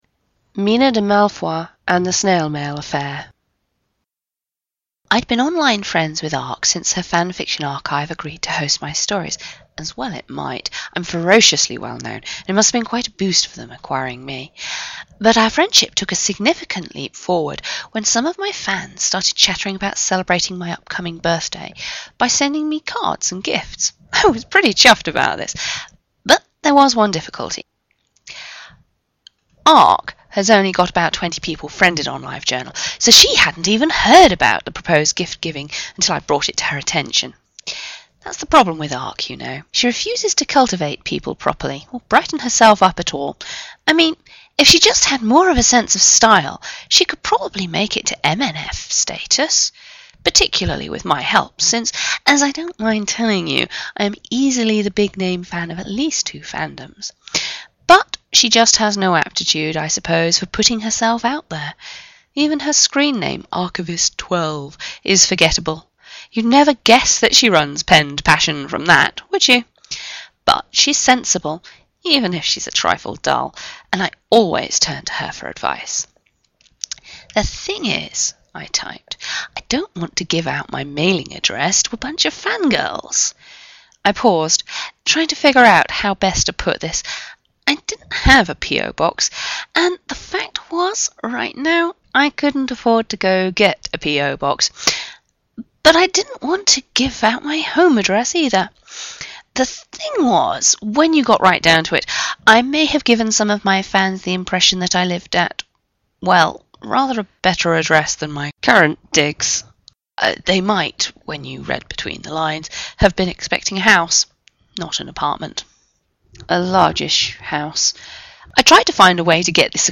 I've done it in a slightly less 'reading the story' and more conversational style, as befits the stories.
If it's unintelligible to non-anglophiles, then I apologise -- let me know and I will pull back on the accent and speed that I'm using.